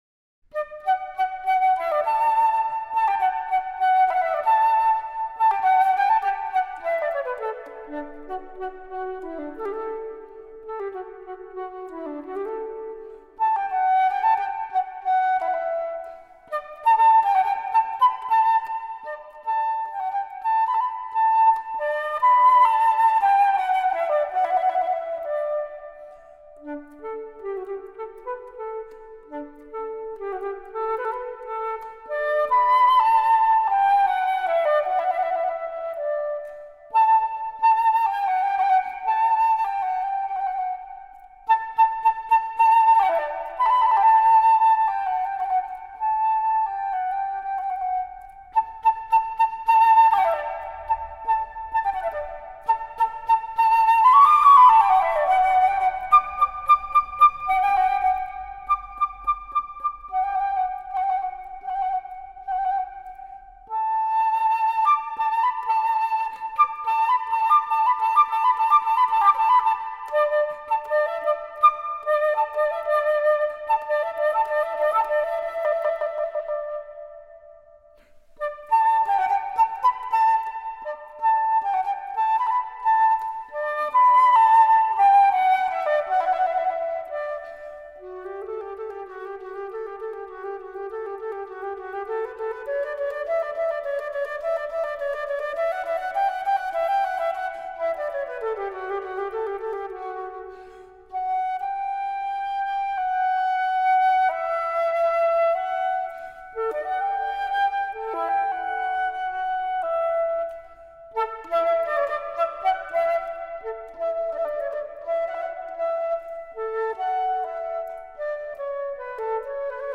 flauto
per flauto solo